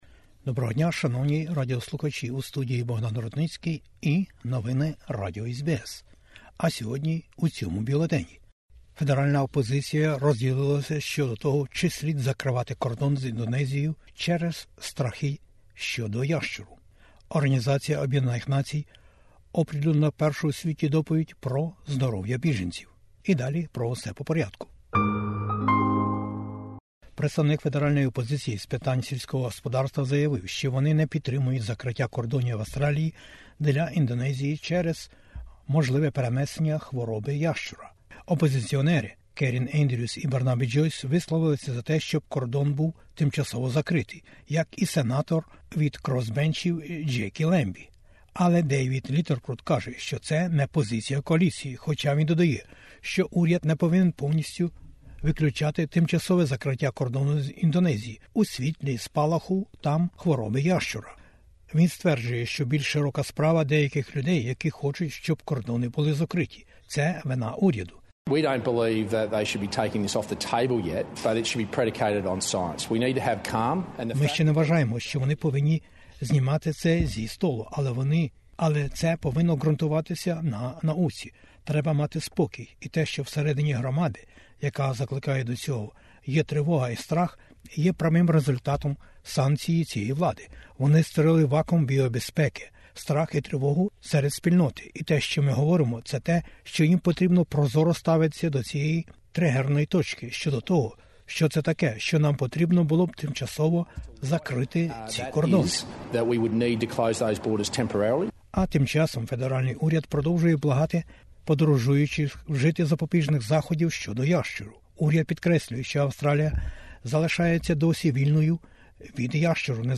Бюлетень SBS новин українською мовою. Федеральний уряд і Федеральна опозиція: про загрози ящура і поточні плани перед засіданням парламенту. COVID-19: реалії щодо короновірусних підваріантів Омікрона BА.4 і BА.5. Про це і більше - на веб-сторінці SBS Ukrainian...